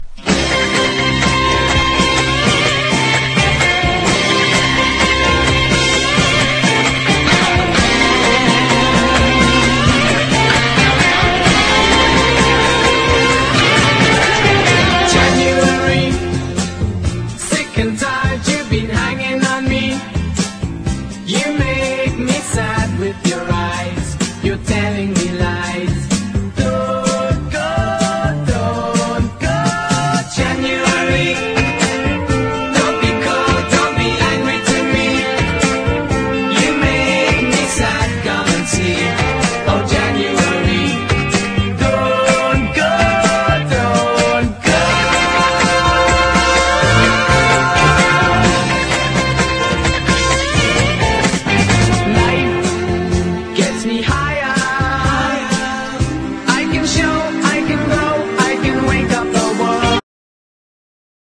BREAKBEATS
LOUD ROCK